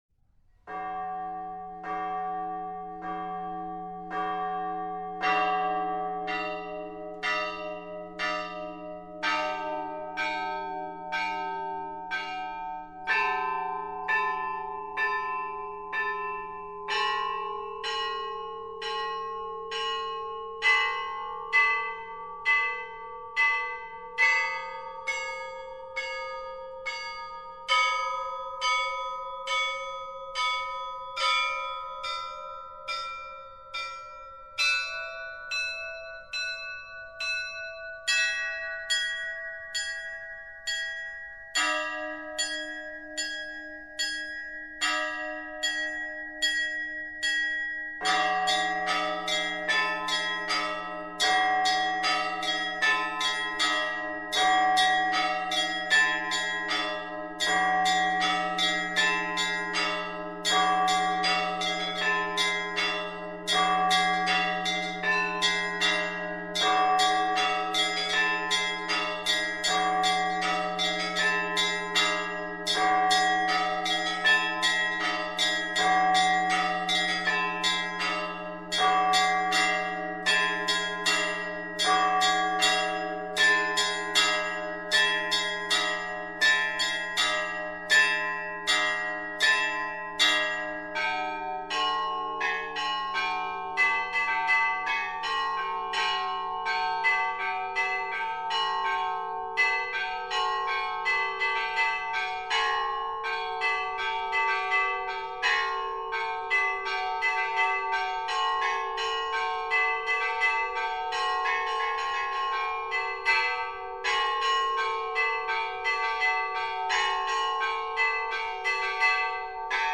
04_Vstrechnyj_perezvon_na_vstrechu_Vladyki.mp3